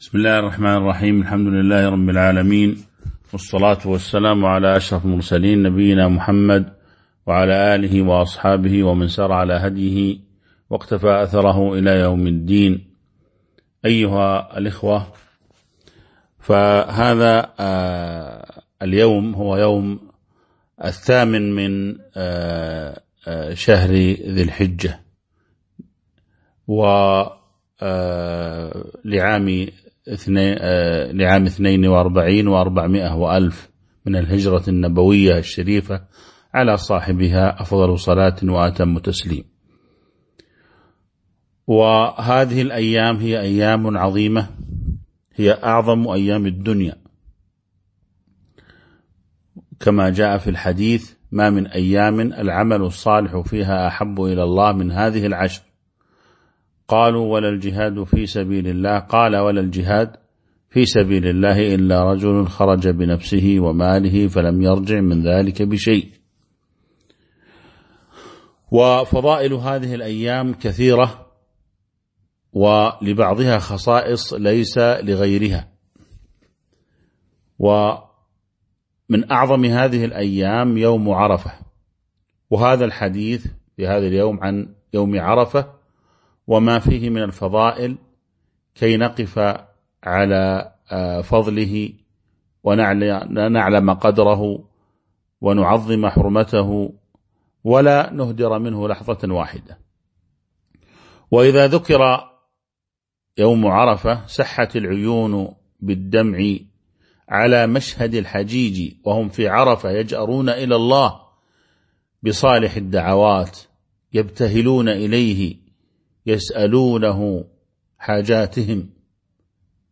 تاريخ النشر ٨ ذو الحجة ١٤٤٢ هـ المكان: المسجد النبوي الشيخ